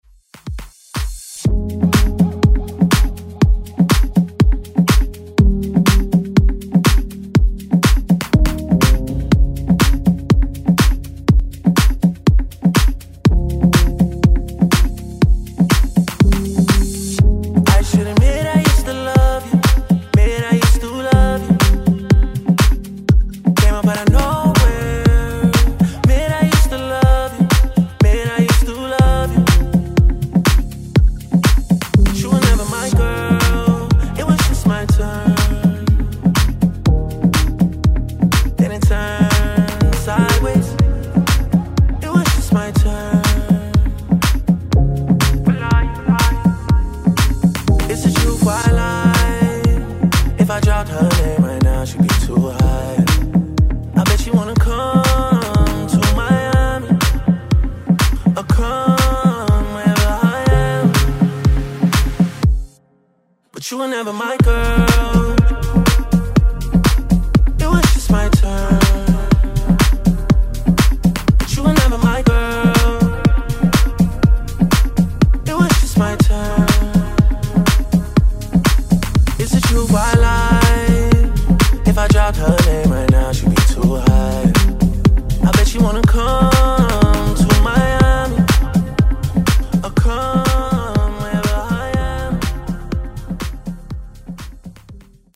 Genres: DANCE , FUTURE HOUSE , RE-DRUM
Clean BPM: 123 Time